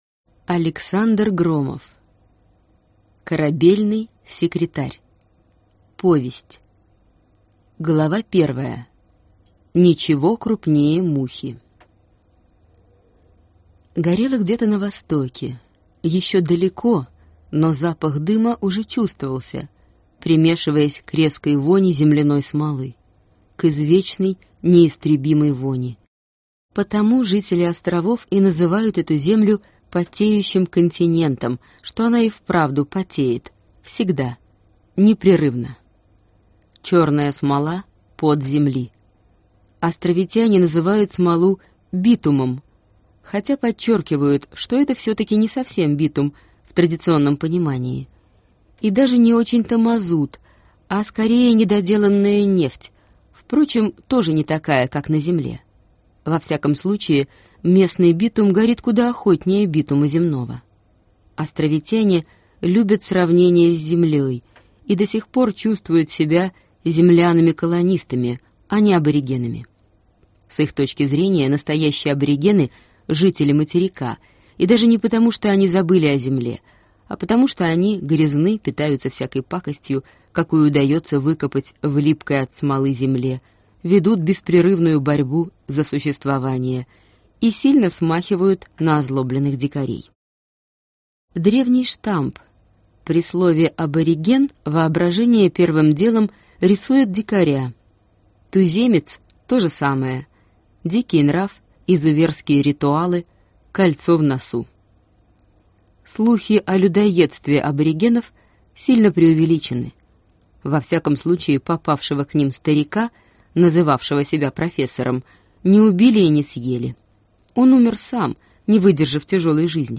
Аудиокнига Корабельный секретарь | Библиотека аудиокниг